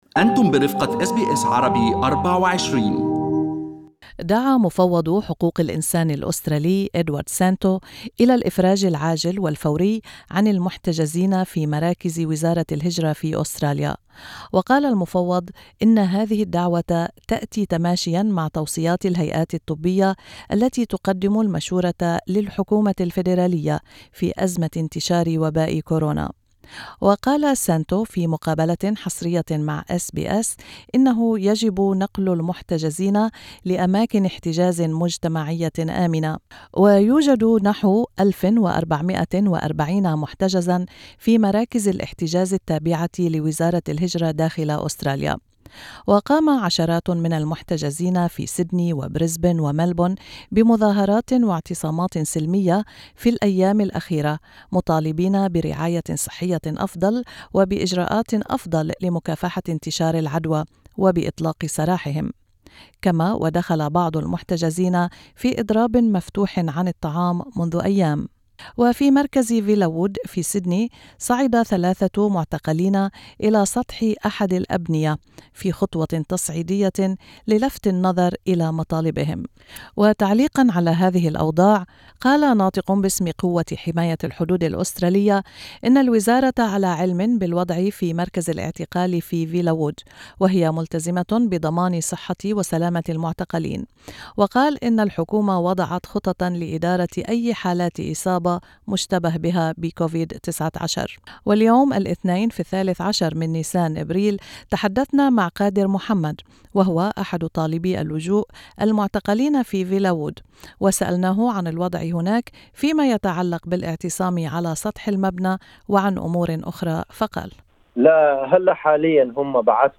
أحد المعتقلين في معتقل فيلاوود التابع لوزارة الهجرة في سيدني يطالب "بالحرية" في الوقت الذي صعّد المحتجزون من اعتصاماتهم "السلمية" مطالبين بحمايتهم من فيروس كورونا.